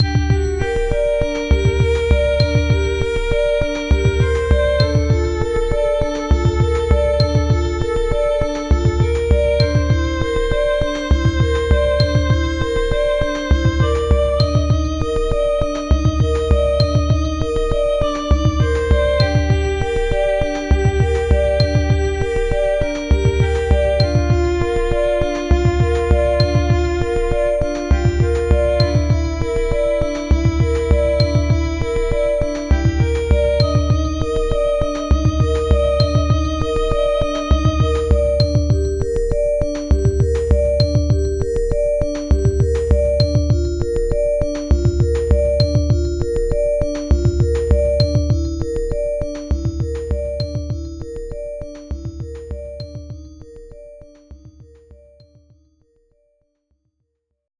melodía
repetitivo
sintetizador
Sonidos: Música